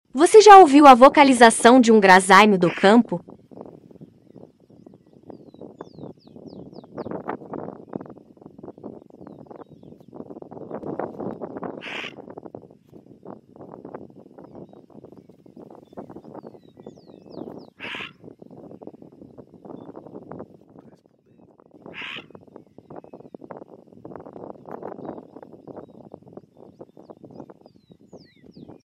Dia chuvoso em campo, mas sound effects free download
🦊 O graxaim-do-campo (Lycalopex gymnocercus) é um canídeo nativo do sul do Brasil. Esse estava chamando outro indivíduo para dividirem uma carcaça que estava no pasto ao lado.